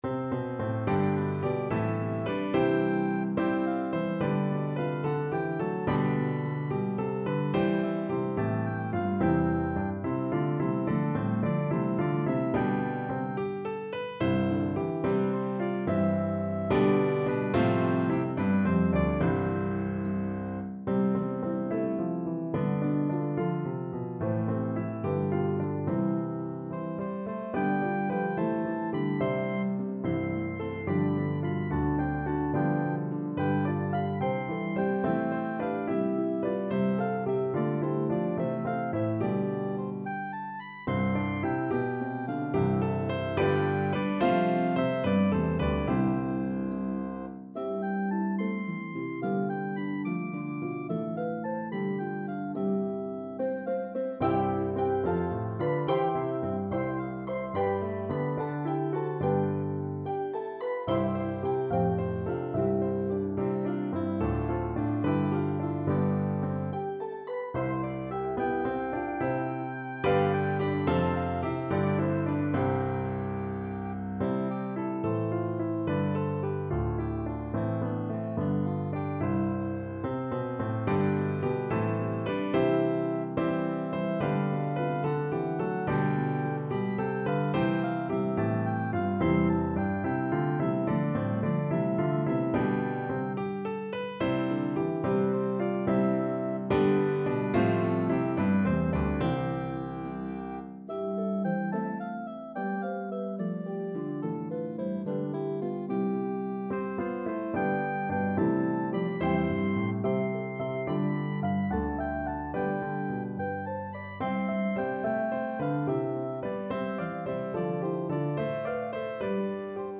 It is now both an Advent Hymn and Christmas Carol.